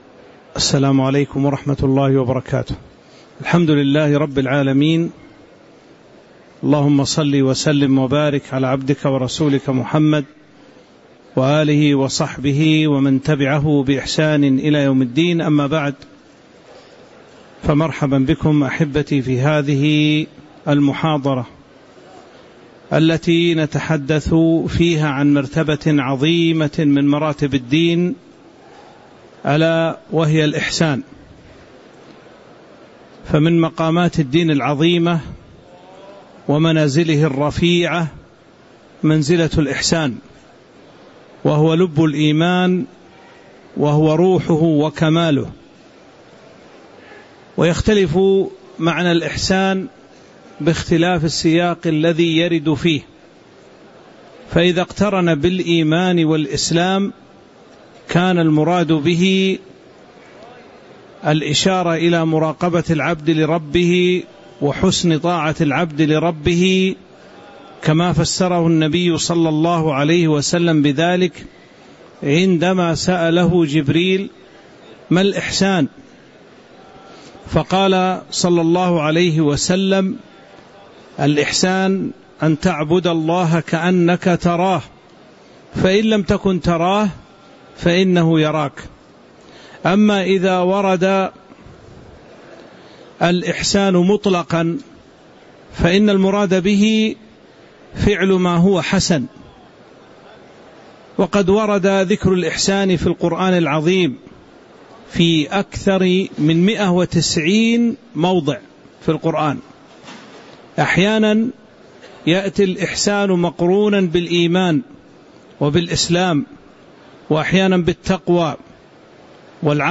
تاريخ النشر ١ محرم ١٤٤٧ هـ المكان: المسجد النبوي الشيخ